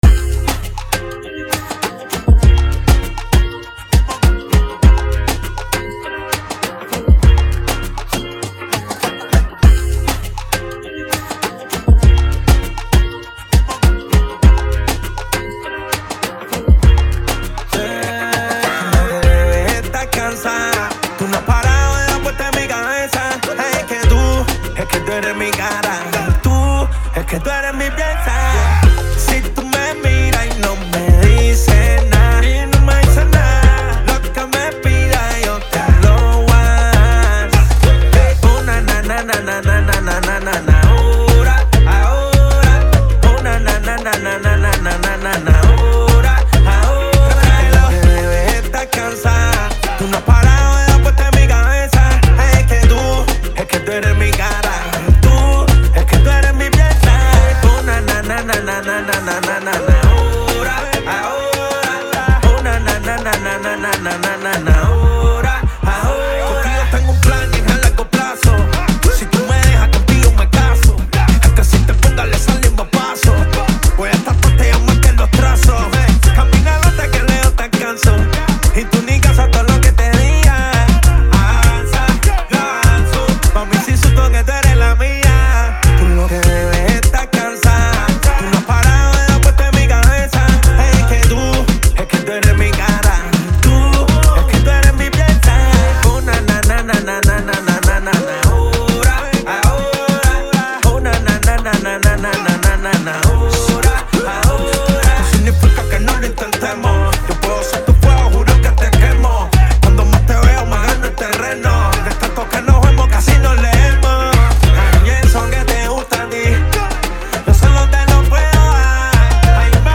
Genre: Reggaeton.